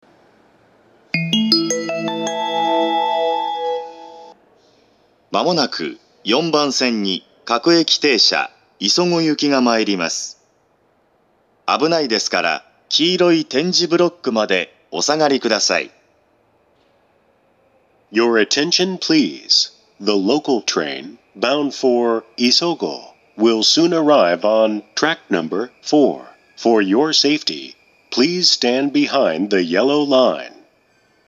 ４番線接近放送